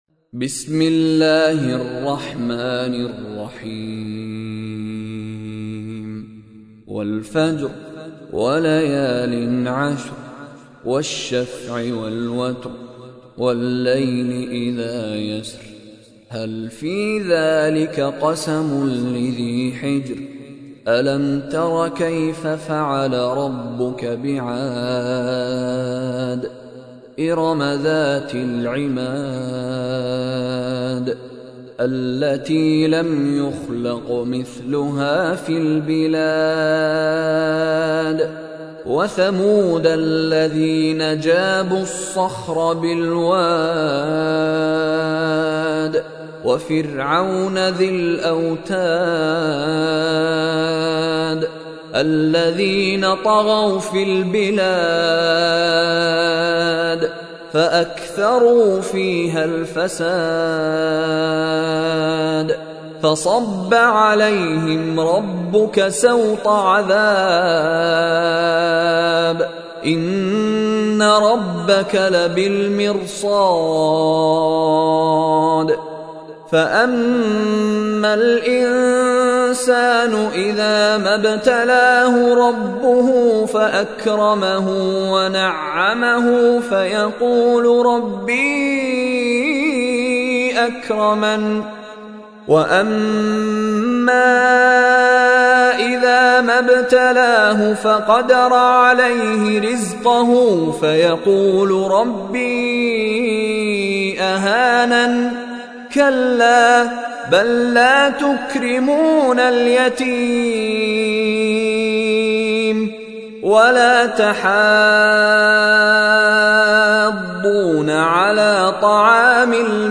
استمع أو حمل سُورَةُ الفَجۡرِ بصوت الشيخ مشاري راشد العفاسي بجودة عالية MP3.
سُورَةُ الفَجۡرِ بصوت الشيخ مشاري راشد العفاسي